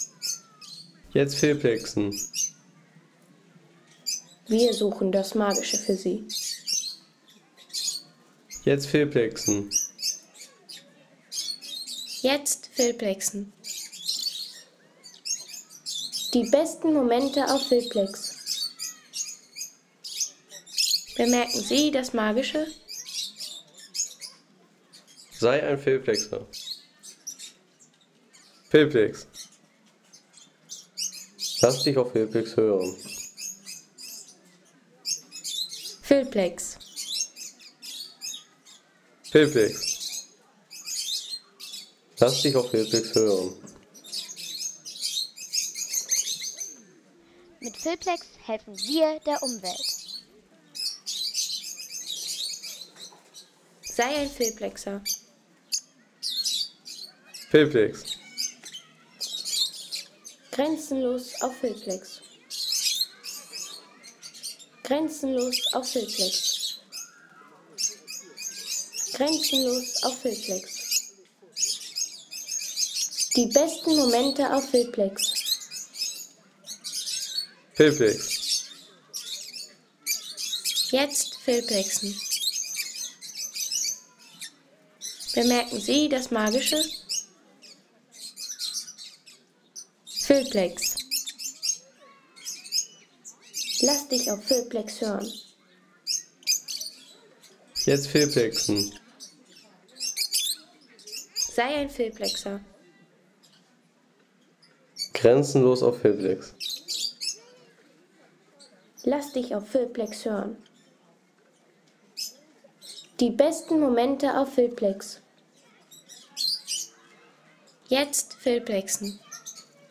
Rosenköpfchen / Lovebird
Gesellige Papageien in großer Gruppe.